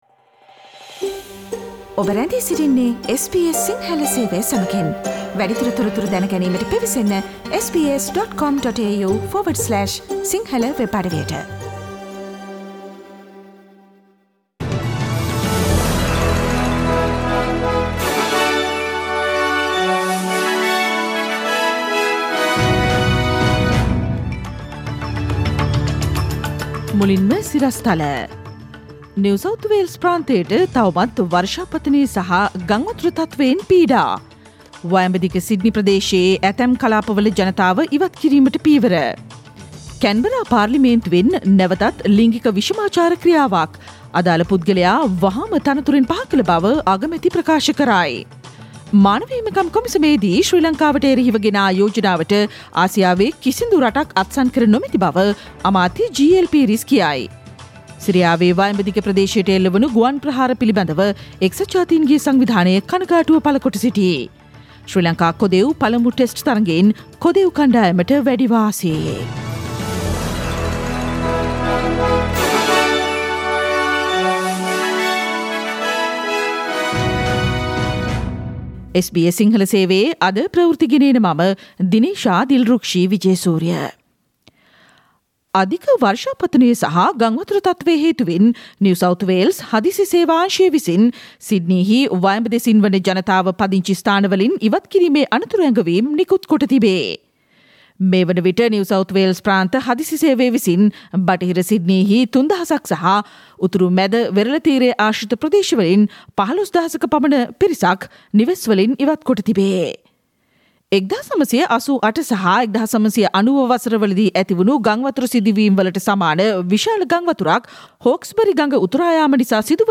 පාර්ලිමේන්තුවේ ආගමික කටයුතු කාමරයේ ලිංගික කටයුතු, ගණිකාවනුත් ඇවිත්. අගමැති කේන්තියෙන්: මාර්තු 23 දා SBS සිංහල ප්‍රවෘත්ති